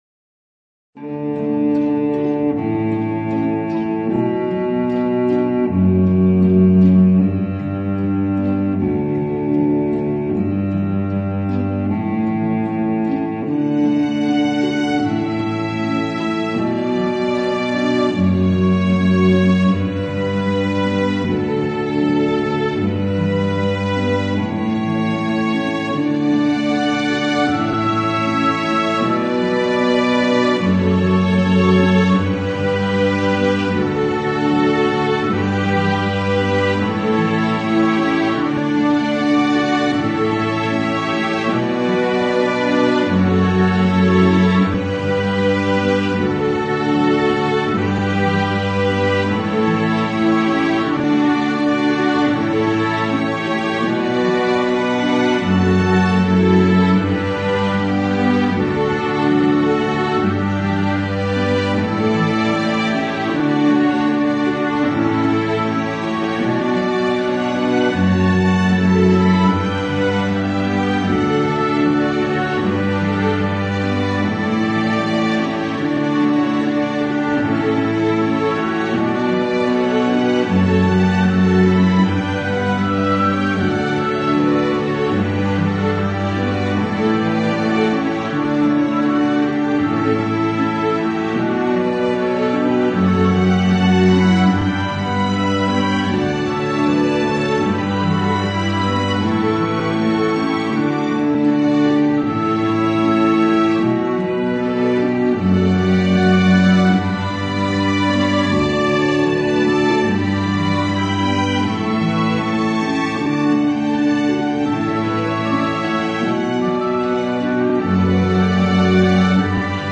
由大提琴、小提琴、竖琴和管弦乐组成
适用于婚礼 / 承诺仪式、婚礼录像、历史剧、历史纪录片、电视、电影 / 电影或任何具有表现力或感伤性质的项目。